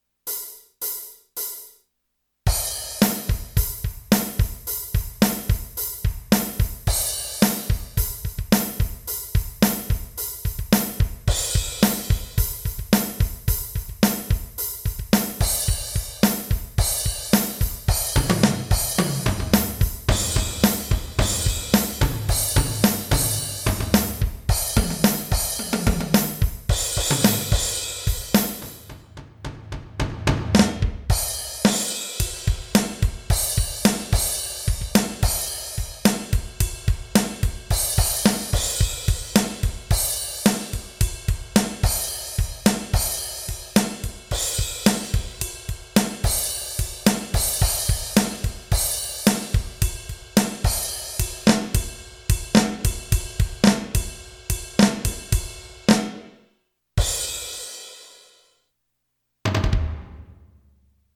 music_brickandmortar_bibishouseofkabob_drums.mp3